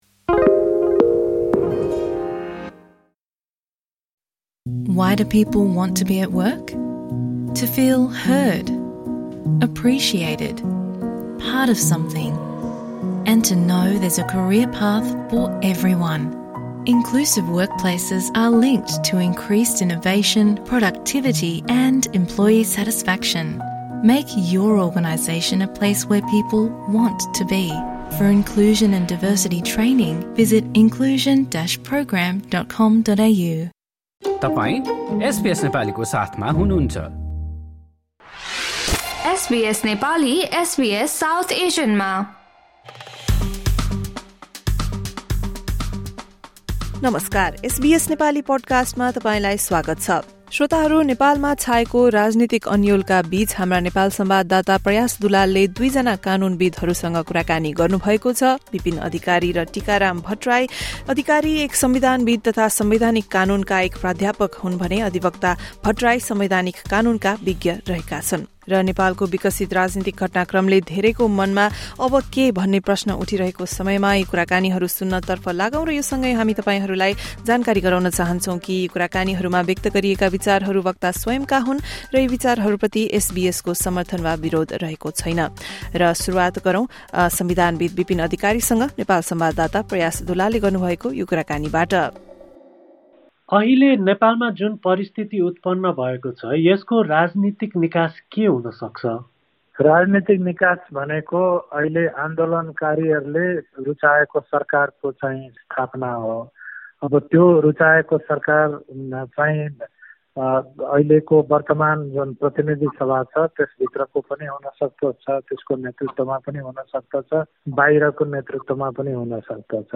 नेपाल सङ्कट: यस्तो भन्छन् दुई कानूनविद्
कुराकानी